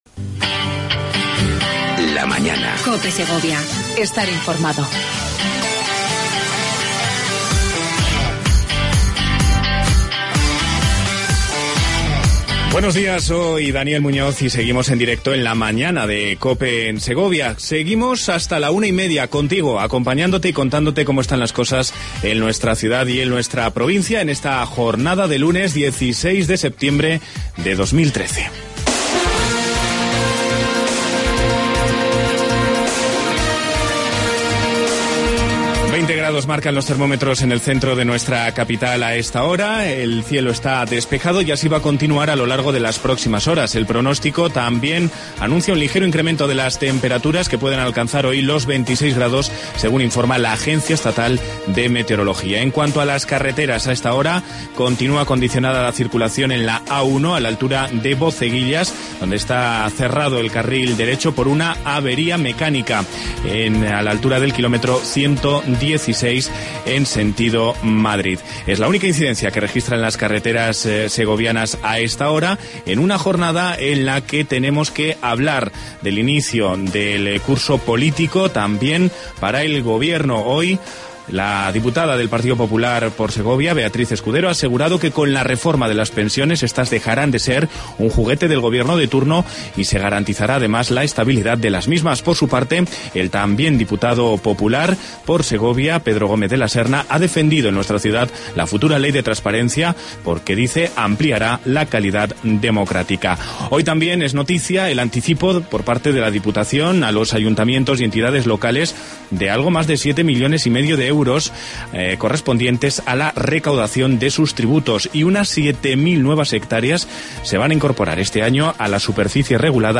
AUDIO: Entrevista con Javier López Escobar, Delegado territorial de La Junta de Castilla y Léon en Segovia.